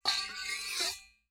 Metal_59.wav